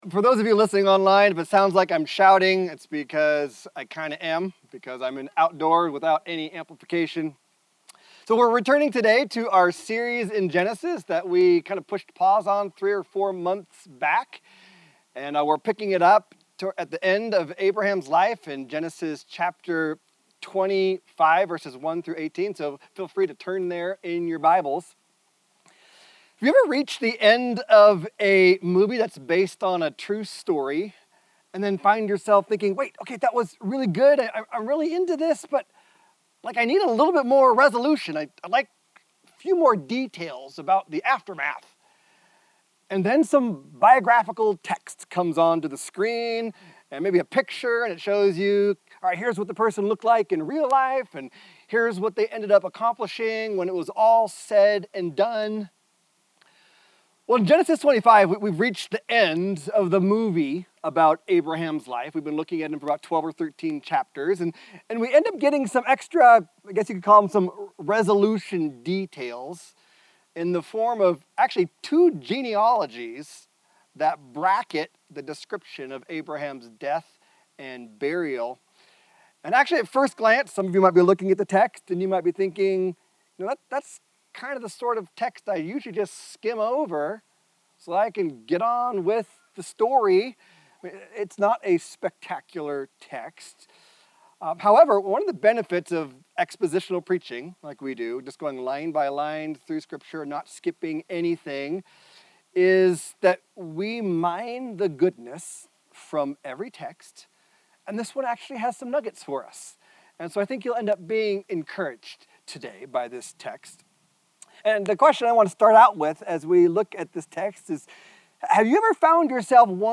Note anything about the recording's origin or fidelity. Reversing Your 'What Ifs?' (Gen 25:1-18) - In The Beginning - Backyard Church Sunday